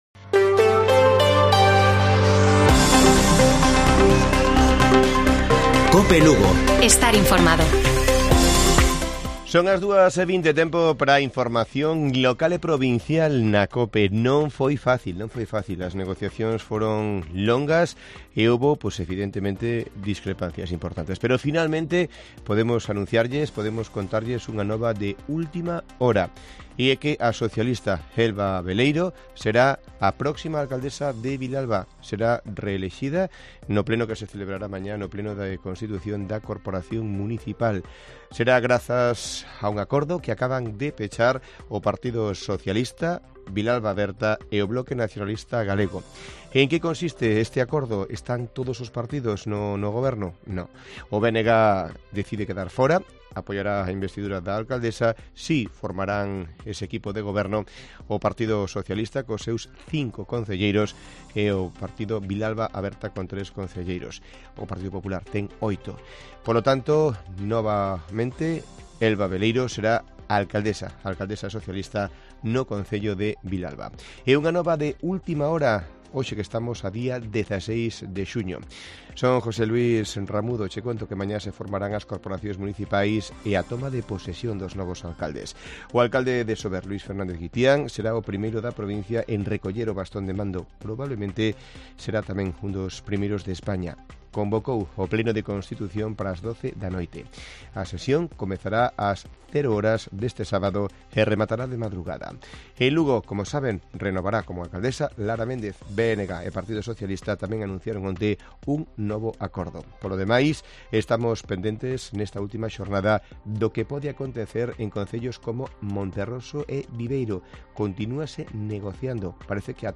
Informativo Mediodía de Cope Lugo.16 de junio. 14:20 horas